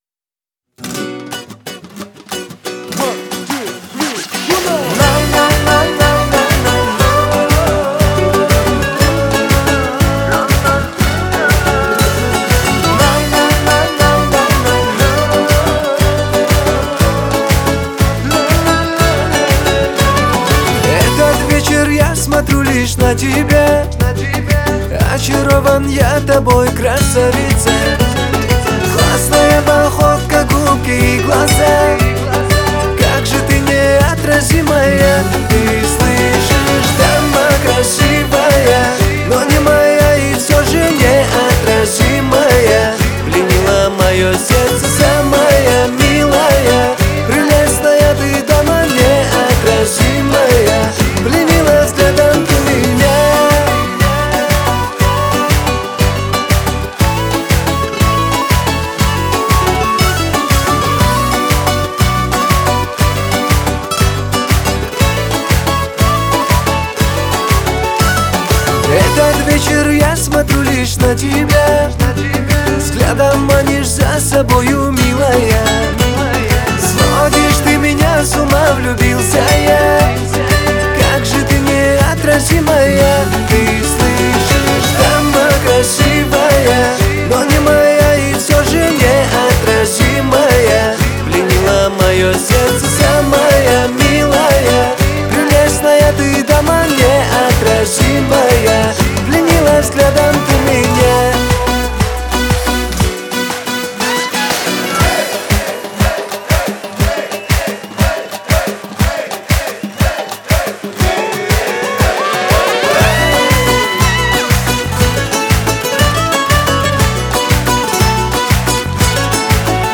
Кавказская музыка